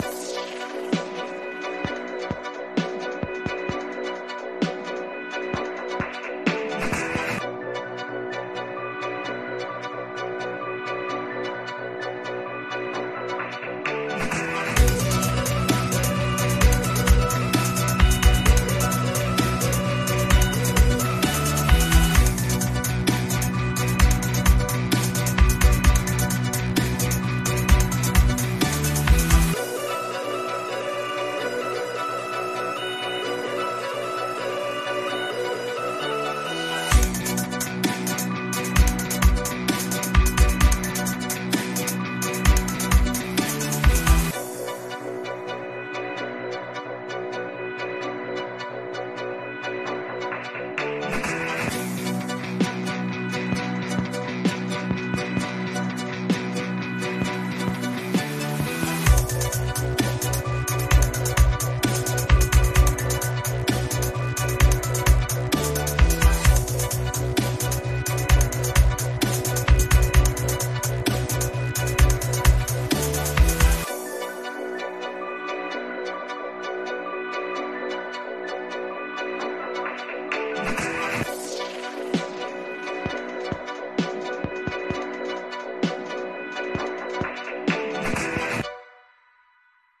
Rock_0322_6.mp3